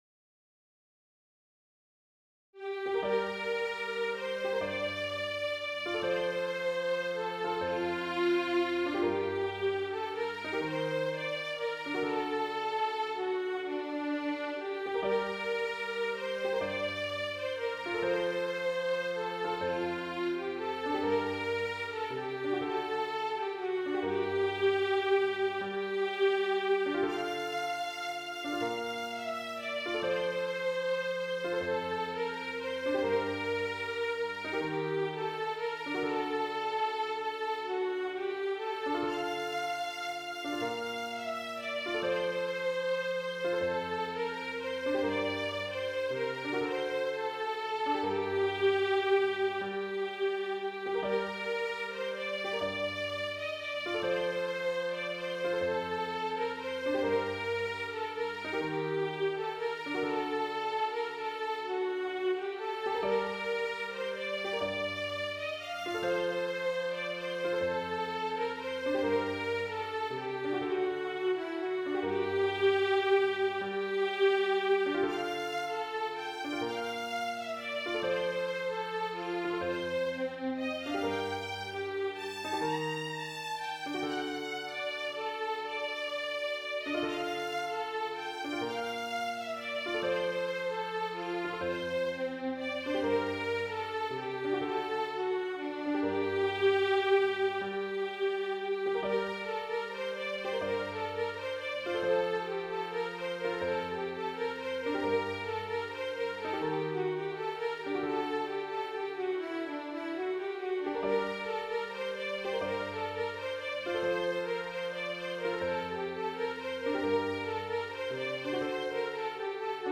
Voicing/Instrumentation: SATB , Oboe